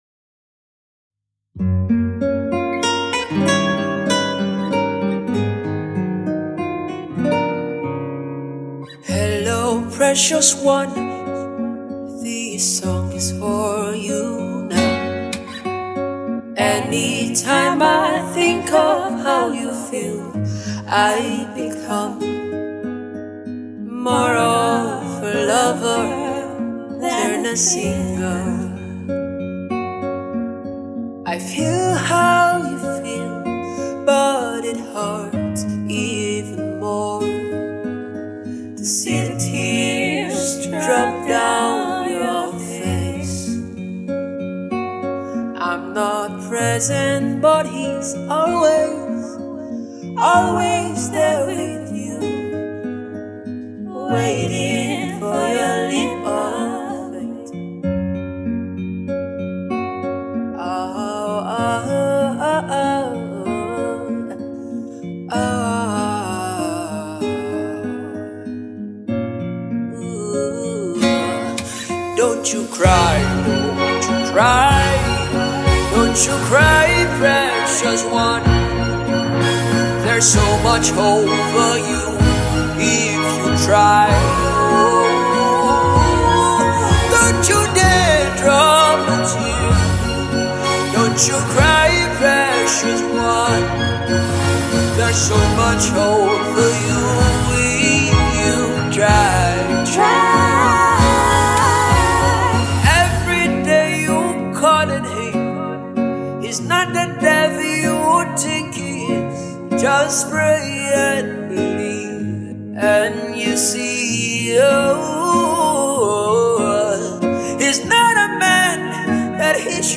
This soothing sound